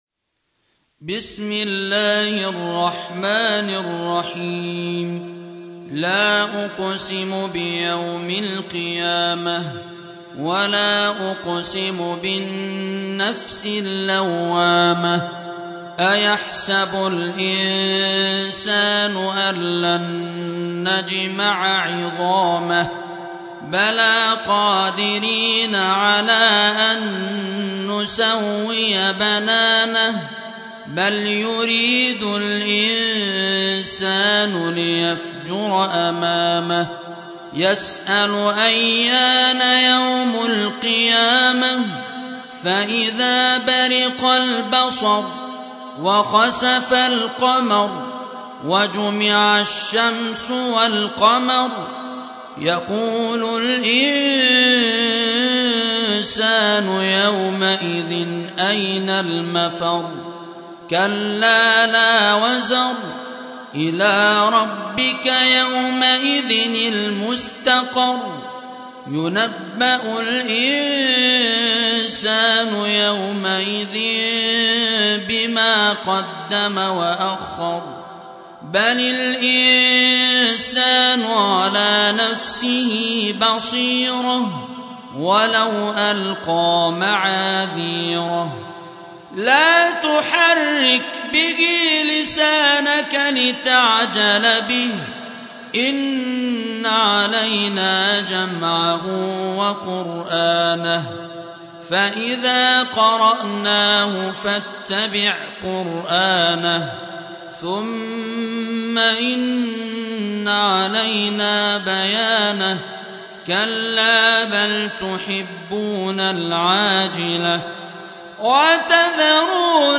Surah Repeating تكرار السورة Download Surah حمّل السورة Reciting Murattalah Audio for 75. Surah Al-Qiy�mah سورة القيامة N.B *Surah Includes Al-Basmalah Reciters Sequents تتابع التلاوات Reciters Repeats تكرار التلاوات